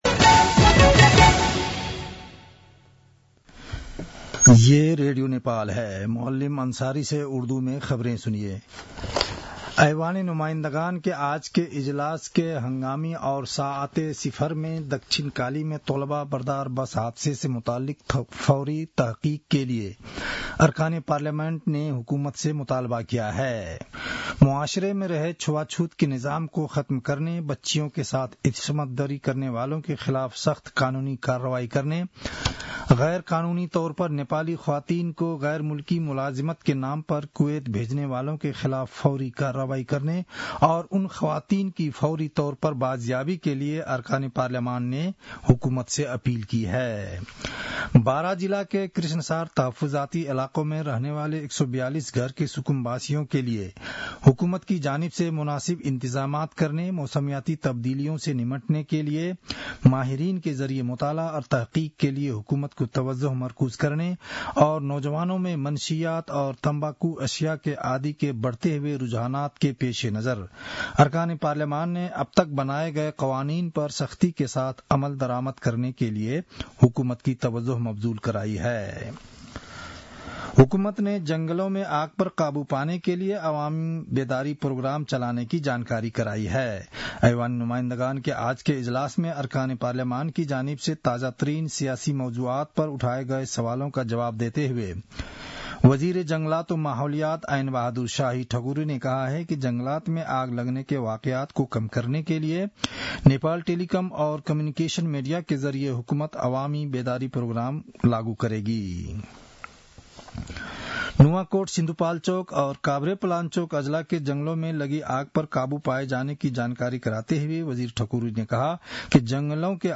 उर्दु भाषामा समाचार : २९ माघ , २०८१
Urdu-news-10-28.mp3